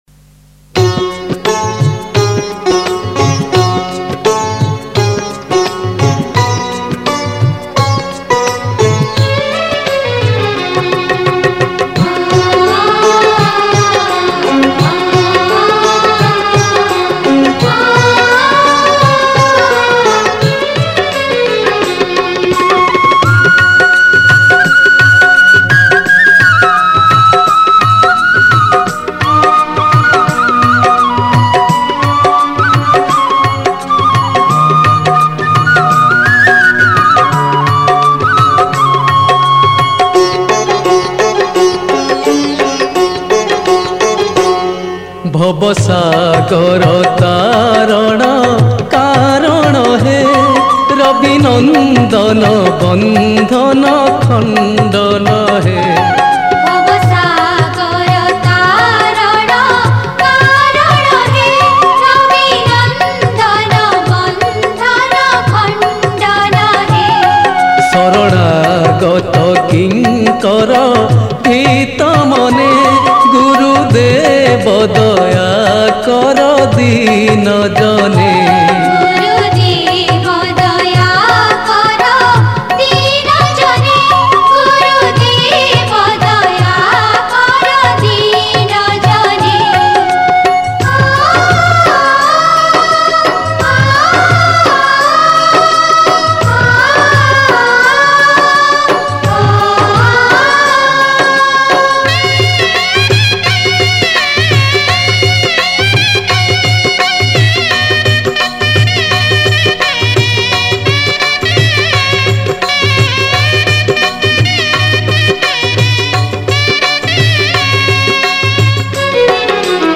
Category: Prathana